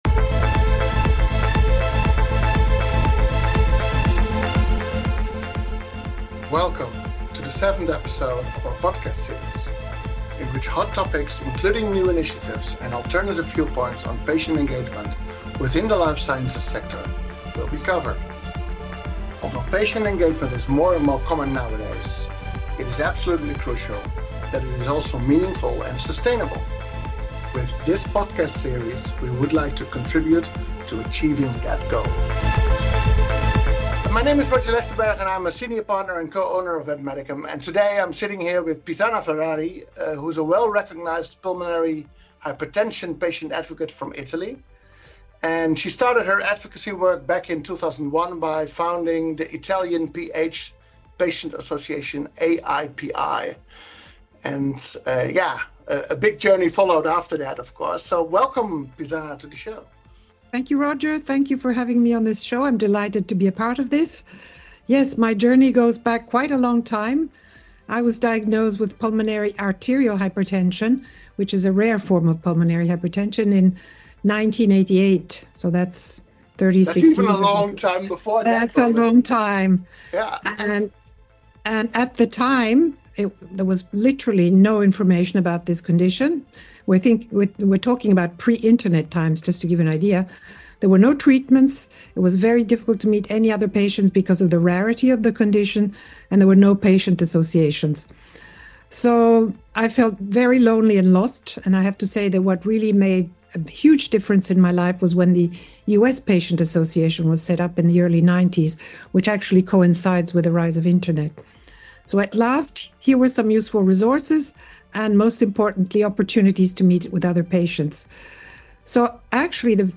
Join this impactful conversation as they delve into what has been achieved in PH, what is still needed, the reasons for recently launched Alliance for Pulmonary Hypertension and the future role of patient engagement should play in the field.